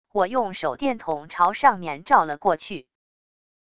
我用手电筒朝上面照了过去wav音频生成系统WAV Audio Player